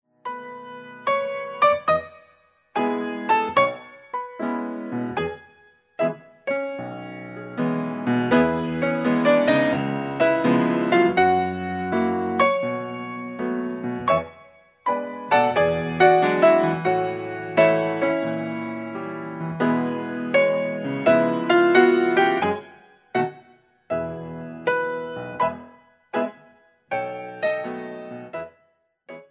All piano CD for Pre- Ballet classes.
Ragtime